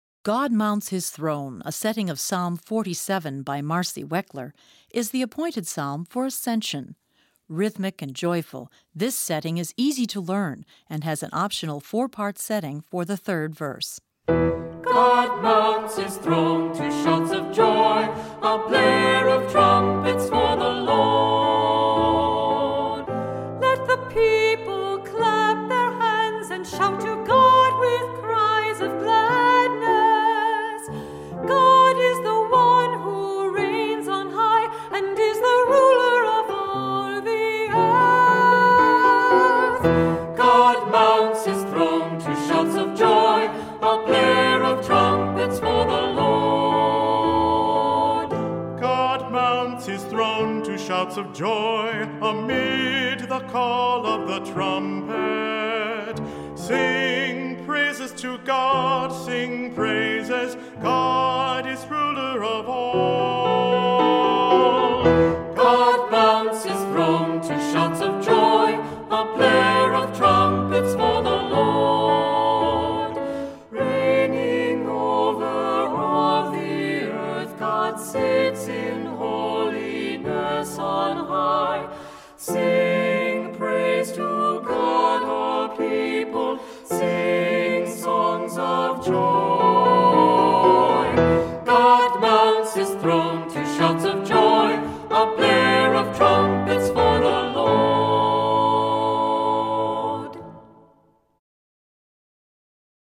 Voicing: Cantor, assembly,SATB